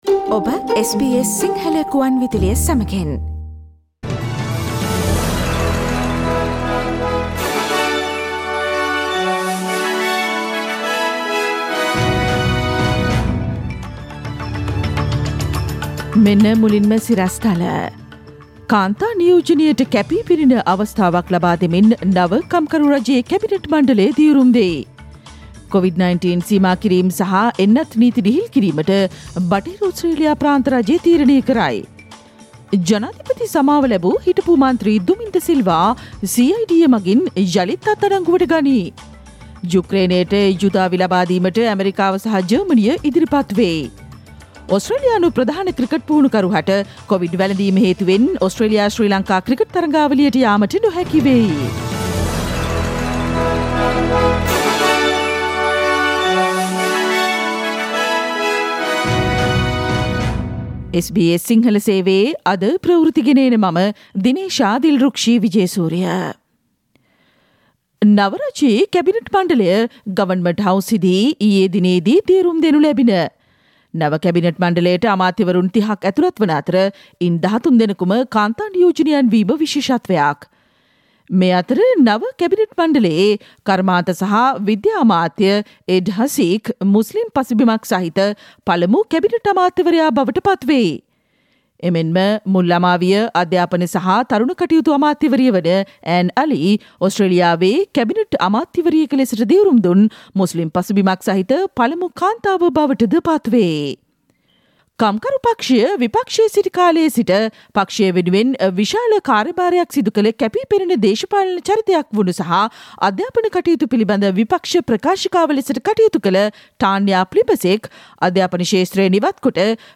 Click on the speaker icon on the image above to listen to the SBS Sinhala Radio news bulletin on Thursday 02 June 2022.